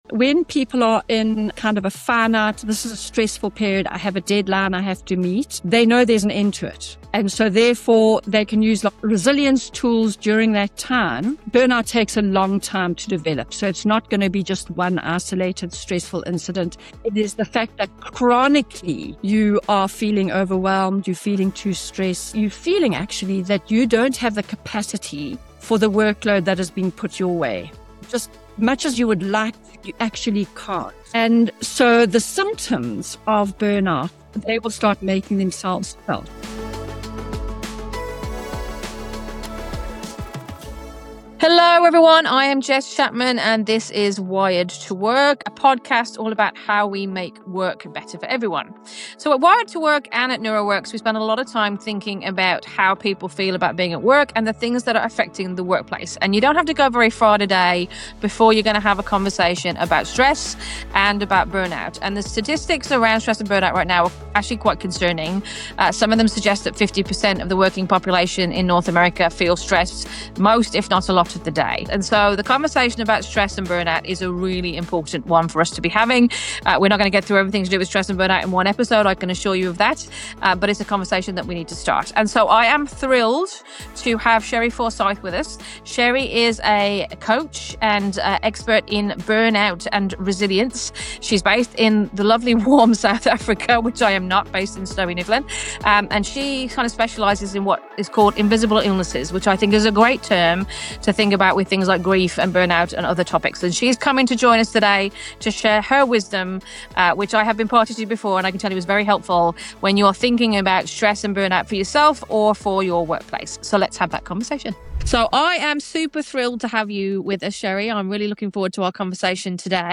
It’s a grounded, practical conversation about performance, pressure, and what it really takes to build healthier, more sustainable workplaces.